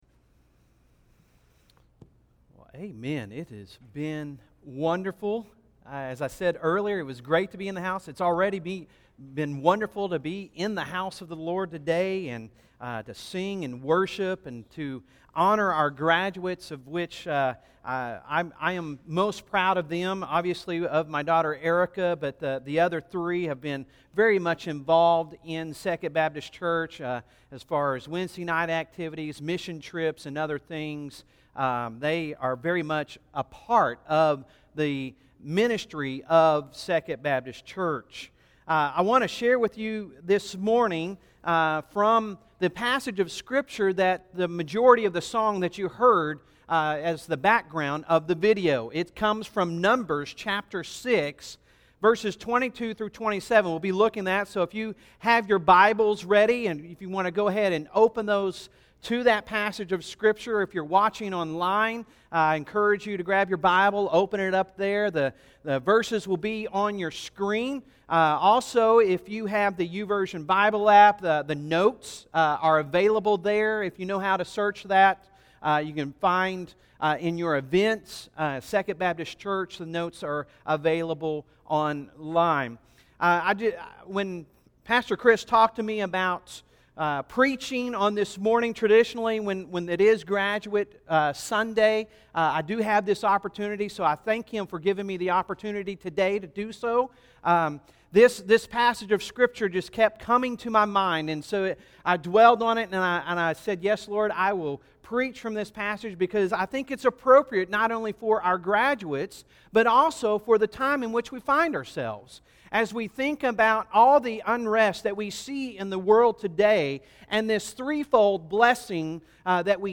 Sunday Sermon June 7, 2020